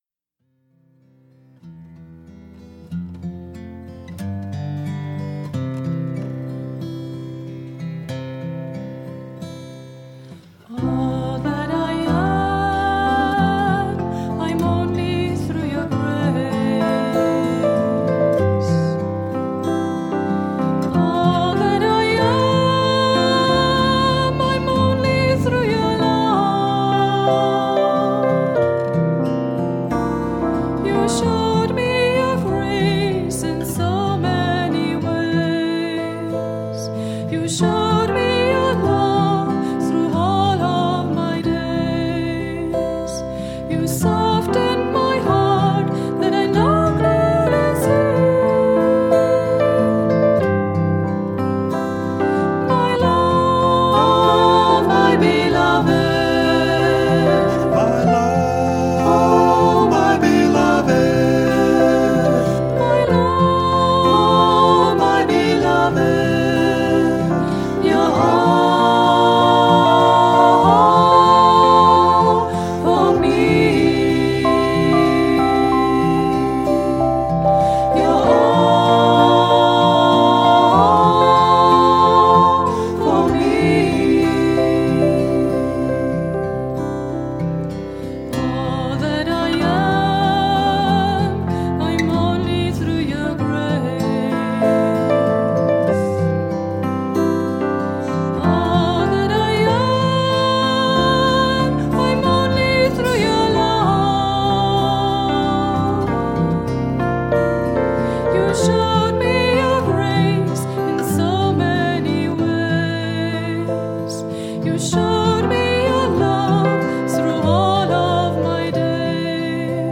1. Devotional Songs
Major (Shankarabharanam / Bilawal)
8 Beat / Keherwa / Adi
Slow